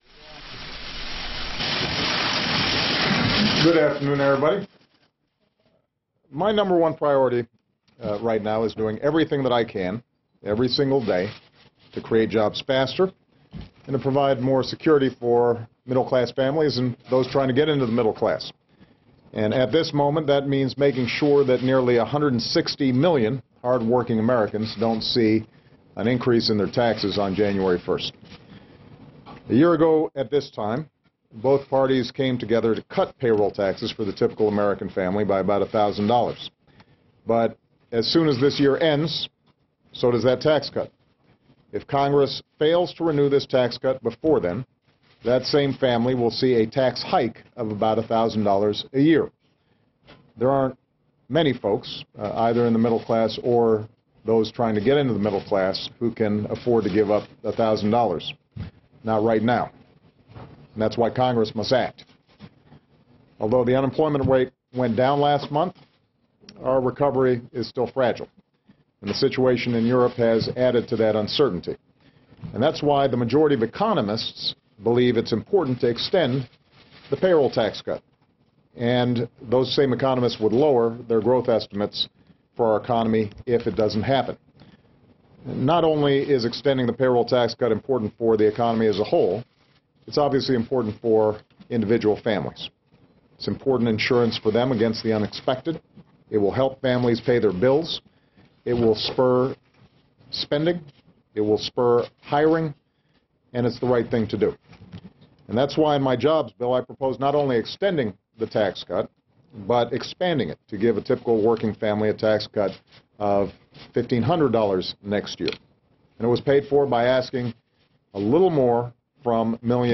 Boradcast on C-SPAN, Dec. 5, 2011.